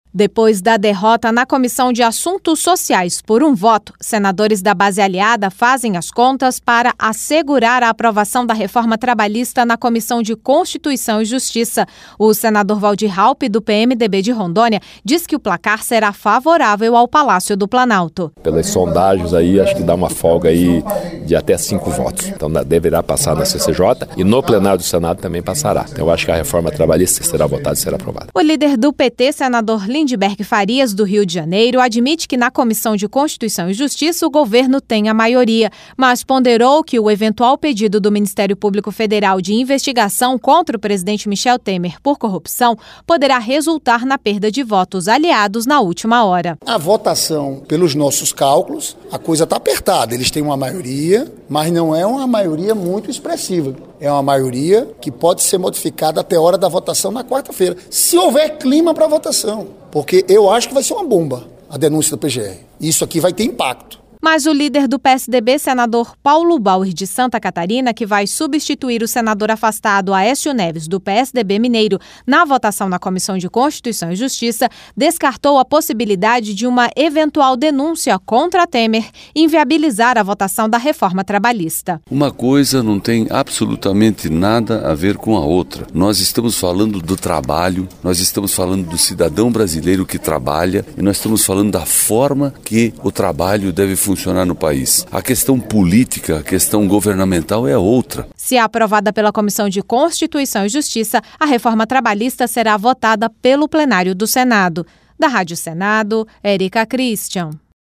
RadioAgência